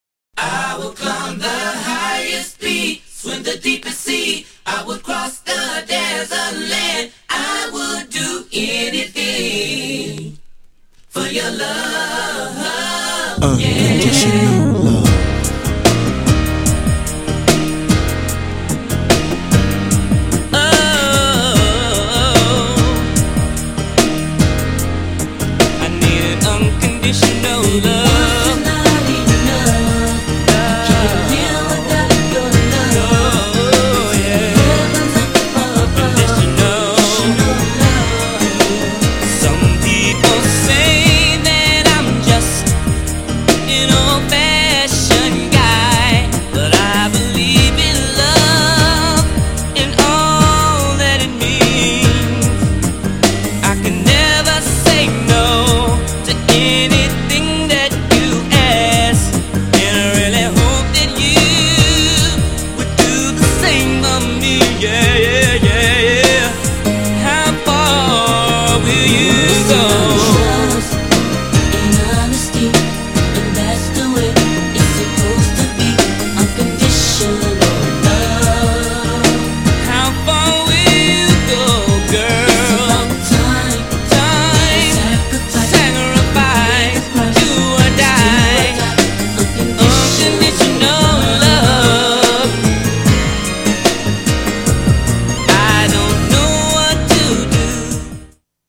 GENRE R&B
BPM 96〜100BPM
ちょいハネ系 # ハートウォームナンバー # メロディアスR&B # 男性コーラスR&B